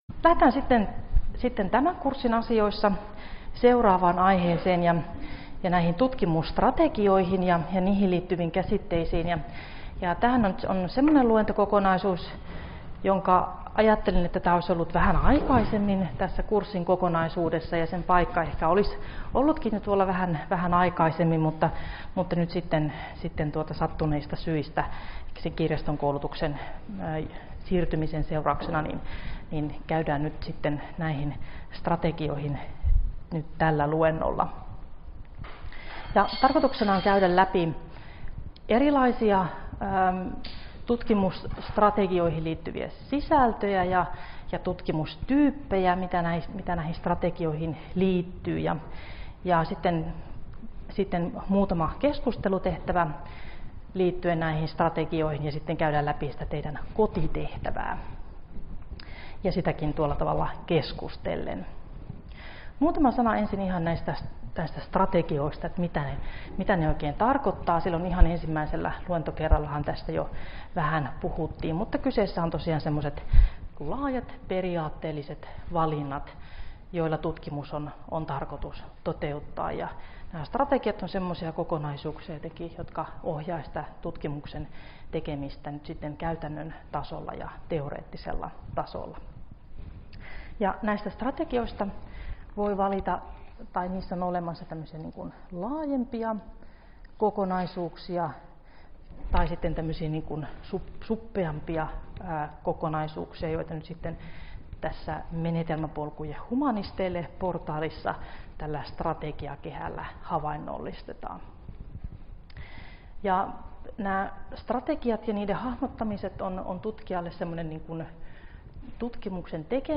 Luento 7 - Tutkimusstrategioihin liittyviä käsitteitä — Moniviestin